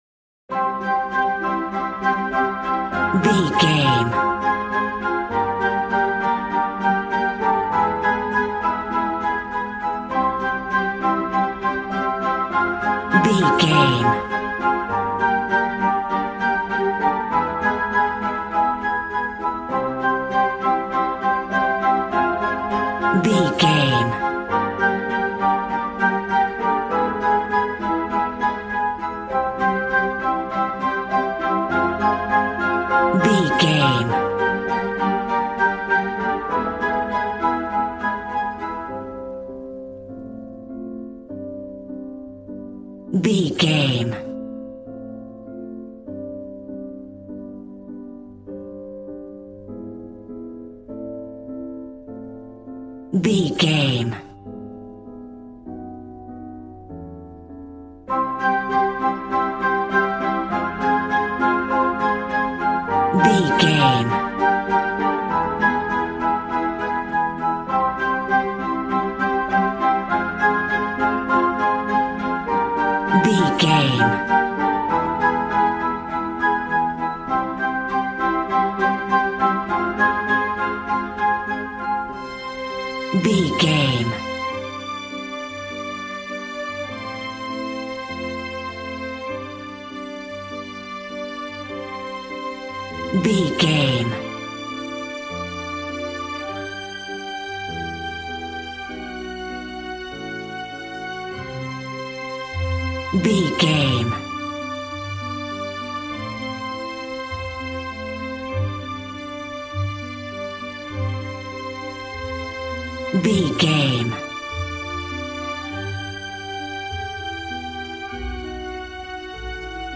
Regal and romantic, a classy piece of classical music.
Ionian/Major
regal
strings
violin
brass